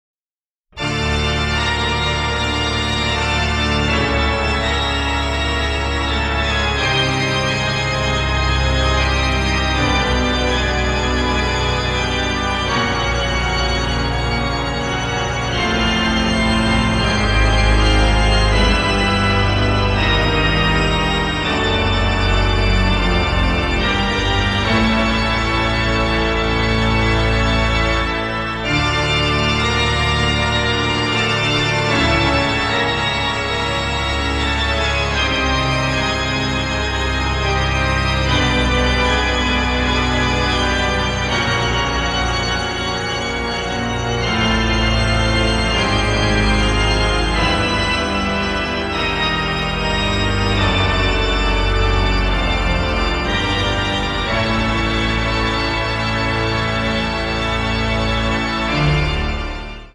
sophisticated avant-garde sound